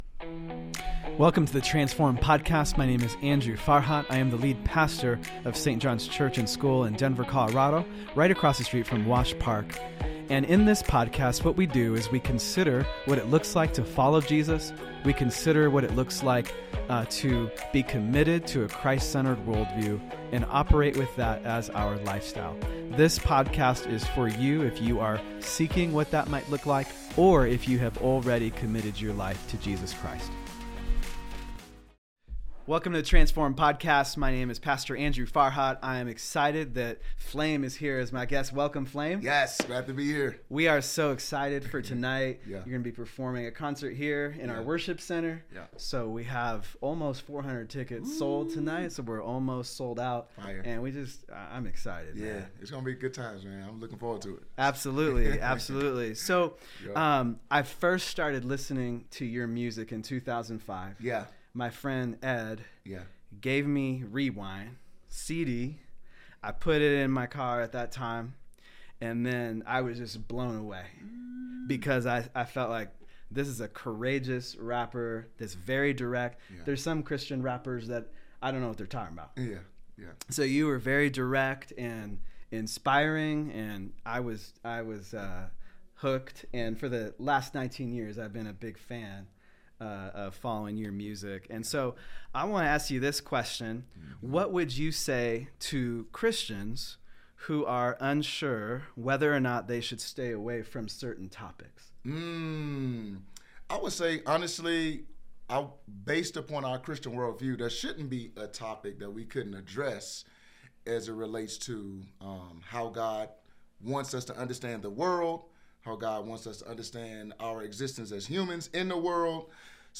Ep. 91 - Q&A with Special Guest Christian Rapper FLAME by Transformed